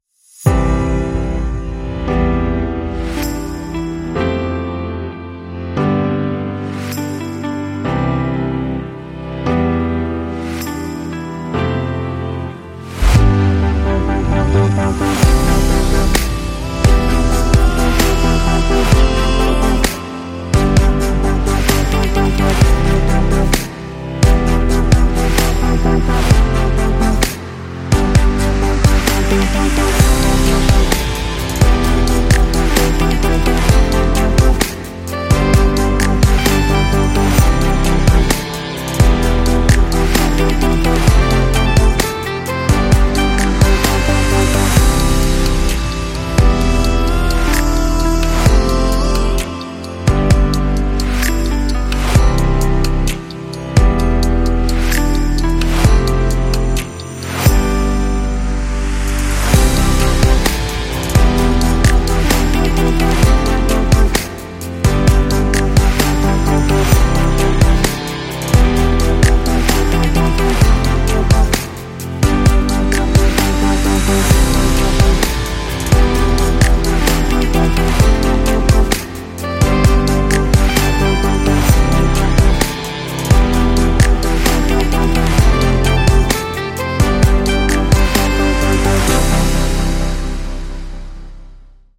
7 - Hip-Hop Lounge Vlog